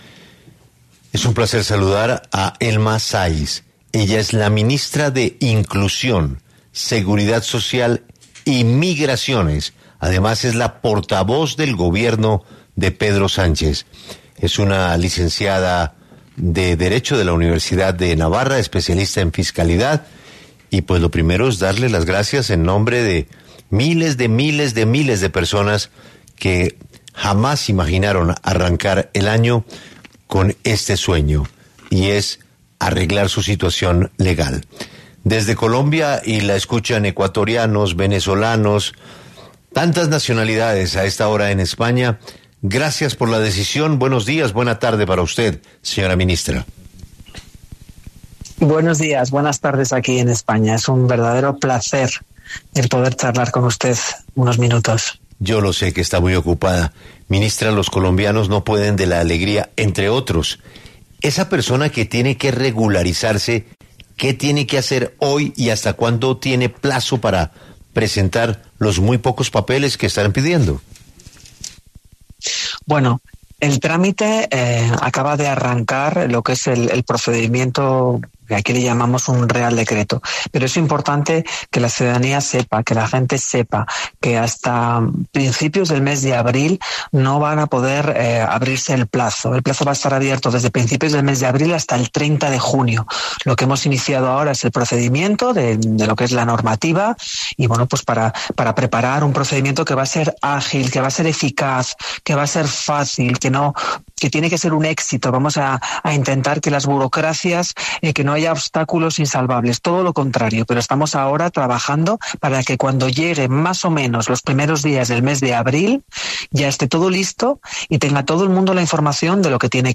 En 6AMW estuvo Elma Saiz, ministra de Inclusión, Seguridad Social y Migraciones de España, para explicar cómo funcionará el Real Decreto
En entrevista con 6AMW, la ministra subrayó que esta medida busca “dignificar” a cientos de miles de personas que ya viven y trabajan en territorio español.